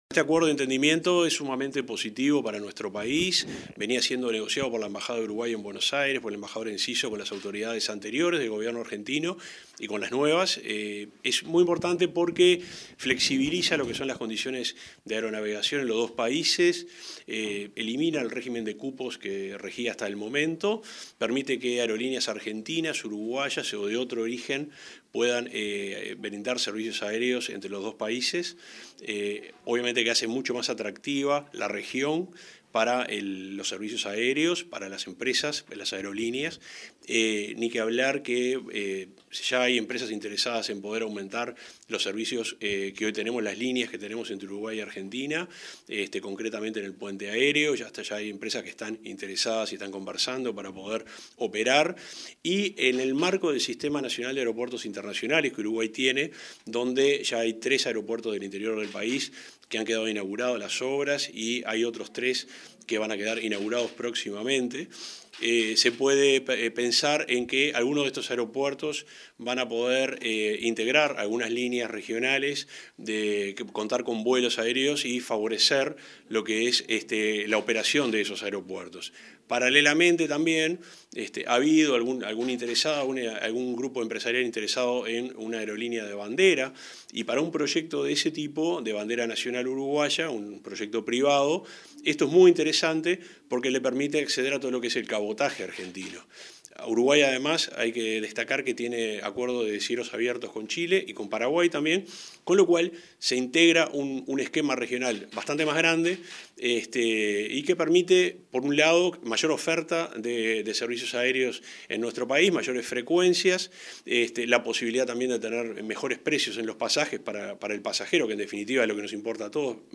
Entrevista al subsecretario de Transporte y Obras Públicas, Juan José Olaizola